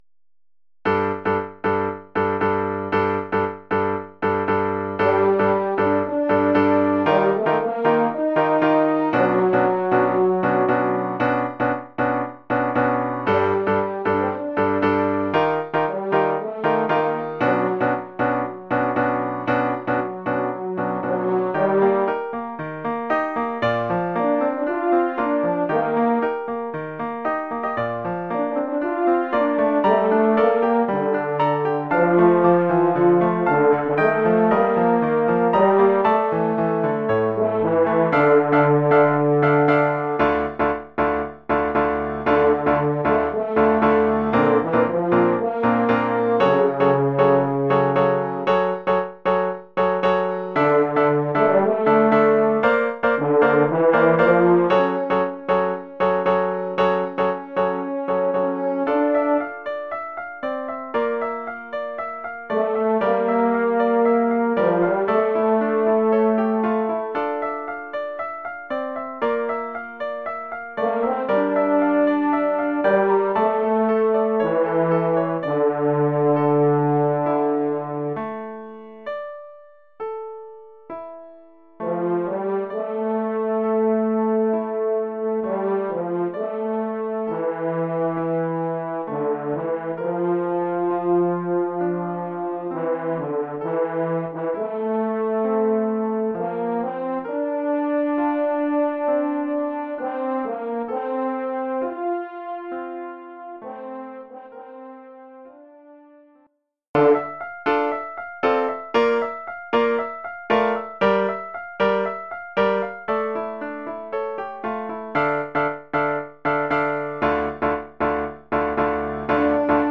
Formule instrumentale : Cor et piano
Oeuvre pour cor d’harmonie et piano.